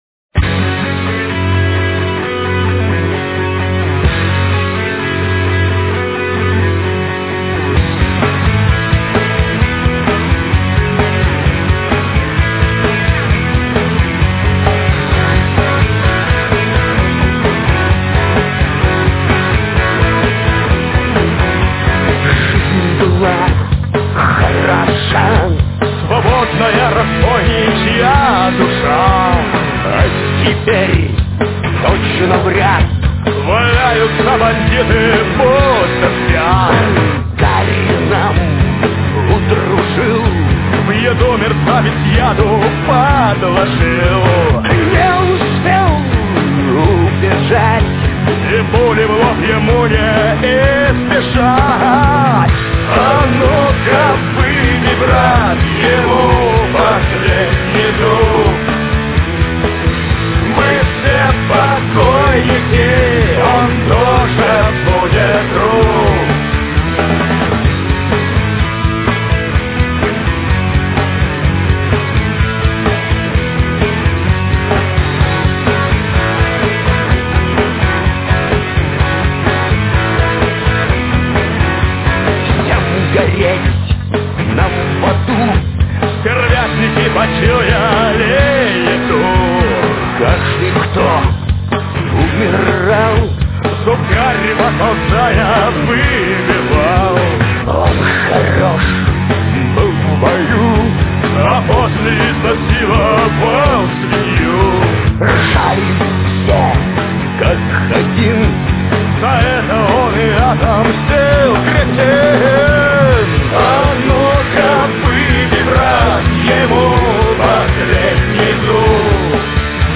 Убойный буклет, 14 драйвовых треков в традиционной